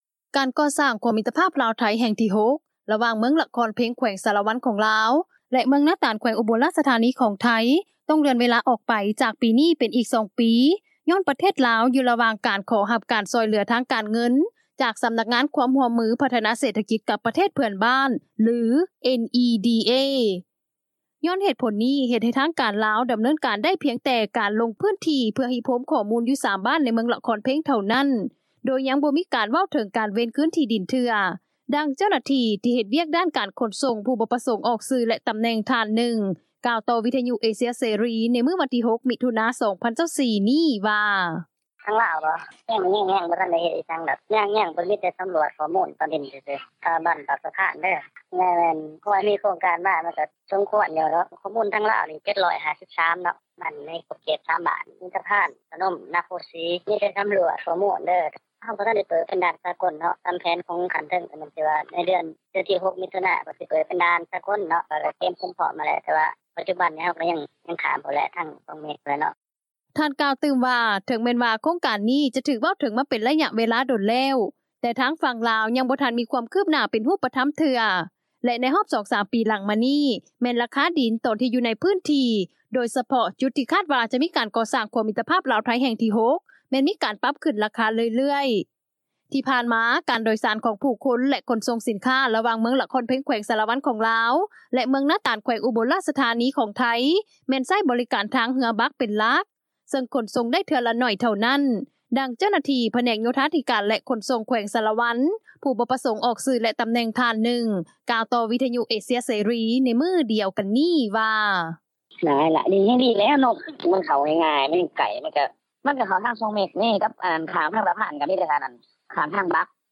ກ່ຽວກັບເລື່ອງນີ້ ຖ້າຫາທາງກລາວ ແລະພາກສ່ວນທີ່ກ່ຽວຂ້ອງ ເລັ່ງກໍ່ສ້າງຂົວມິດຕະພາບລາວ-ໄທ ແຫ່ງທີ 6 ສໍາເລັດ ກໍ່ຈະຊ່ອຍໃຫ້ ການໂດຍສານ ແລະການຂົນສົ່ງສິນຄ້້າ ສະດວກຂຶ້ນ ອີກທັງ ຍັງຊ່ອຍກະຕຸ້ນເສດຖະກິດ ພາຍໃນພື້ນທີ່ນໍາ, ດັ່ງຊາວບ້ານ ຢູ່ເມືອງລະຄອນເພັງ ແຂວງສາລະວັນ ທ່ານໜຶ່ງ ກ່າວວ່າ: